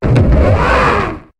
Cri de Mégapagos dans Pokémon HOME.